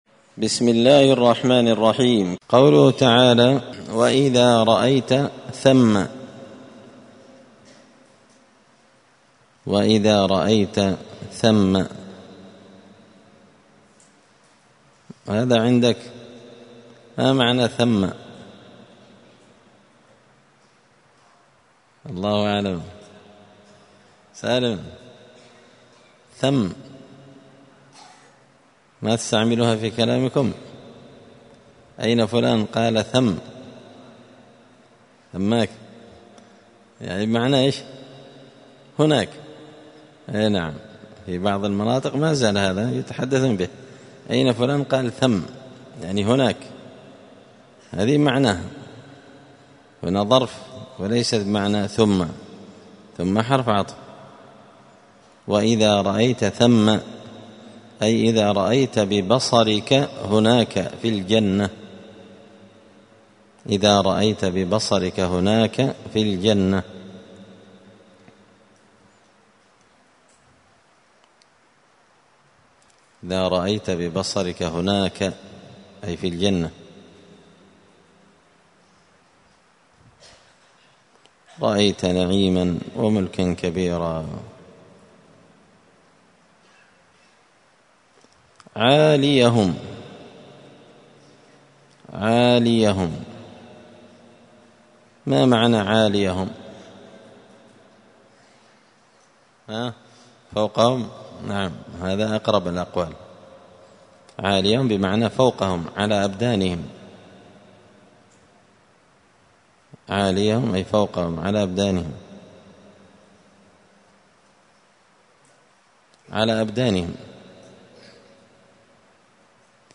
الثلاثاء 25 ربيع الأول 1445 هــــ | الدروس، دروس القران وعلومة، زبدة الأقوال في غريب كلام المتعال | شارك بتعليقك | 72 المشاهدات